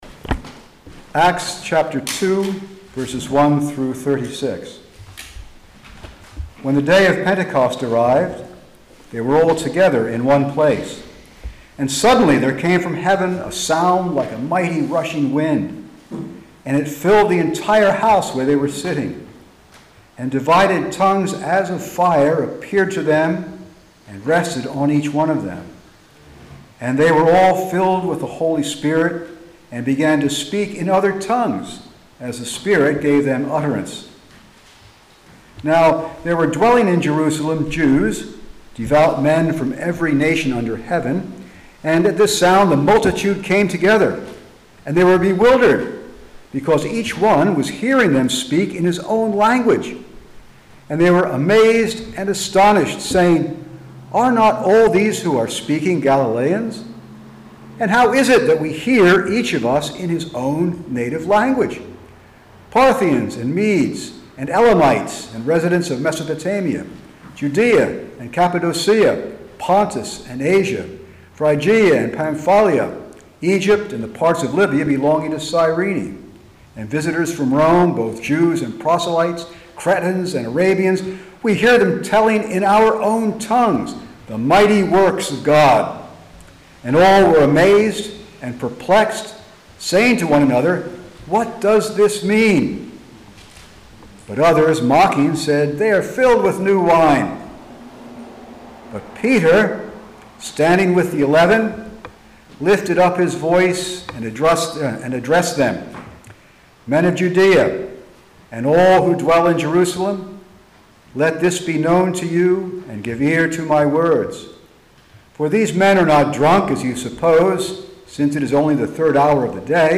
The Cornerstone Church - Sermons